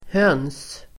Uttal: [hön:s]